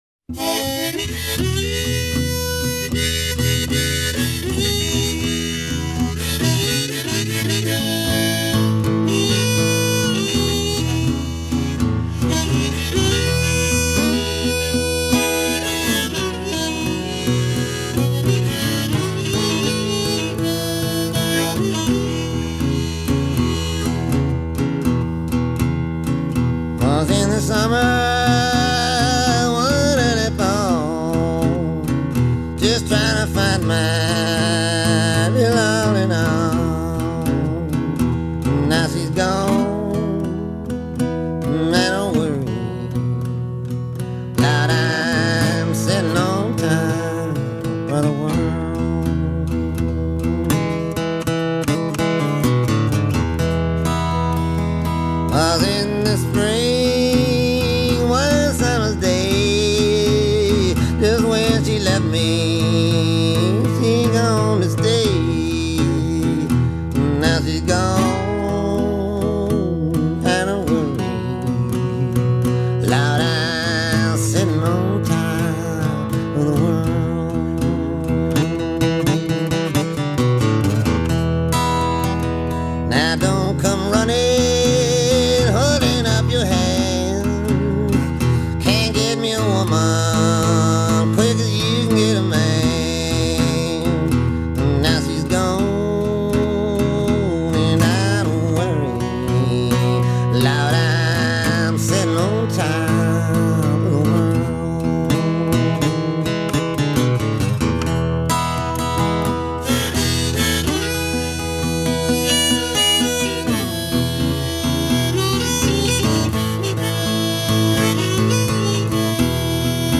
folk covers
croaking